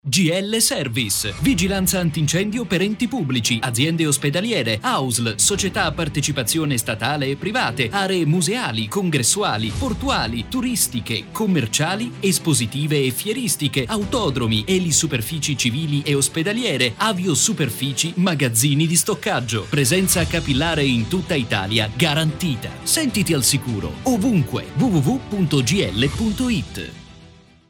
Speaker, doppiatore, Voice over artist
Kein Dialekt
Sprechprobe: Werbung (Muttersprache):
spot tv gl.mp3